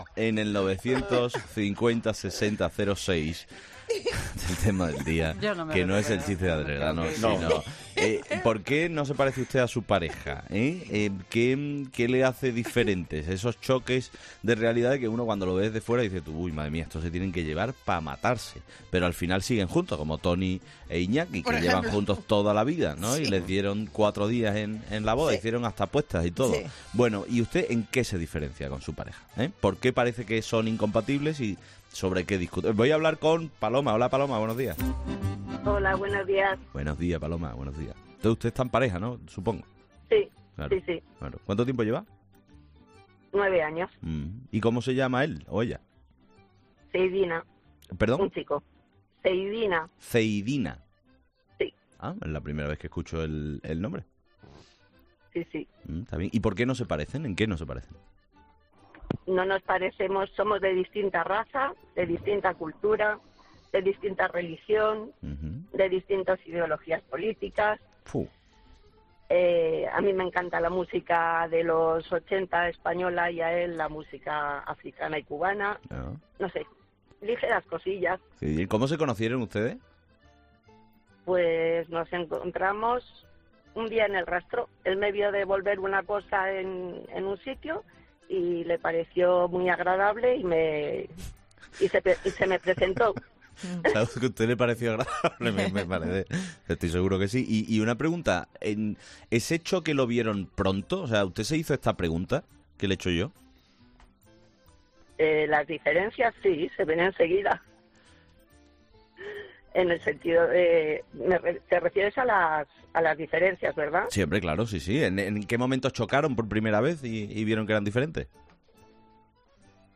En esta ocasión, hablamos con nuestros 'fósforos' de 'Herrera en COPE' sobre lo siguiente: ¿Por qué no se parece usted a su pareja?